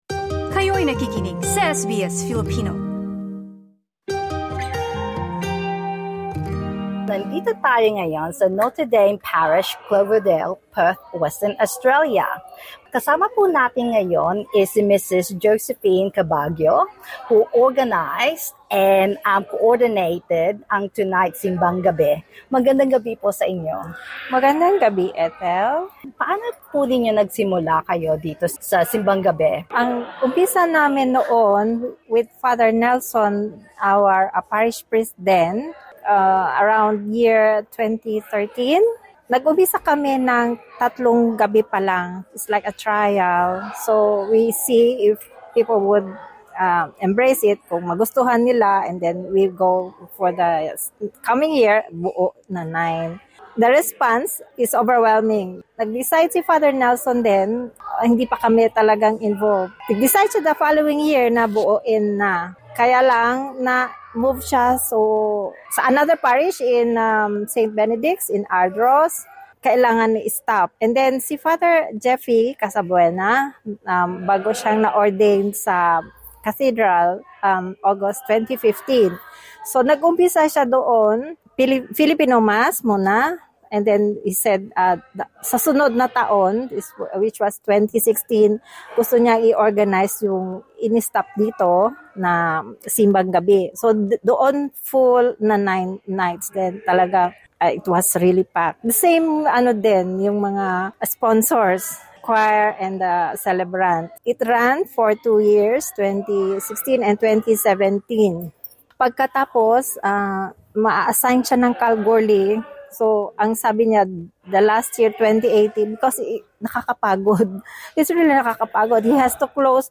Ginaganap ang ika-pitong taon ng Simbang Gabi sa Notre Dame Parish Cloverdale kung saan dumalo ang ilang Pinoy sa Western Australia.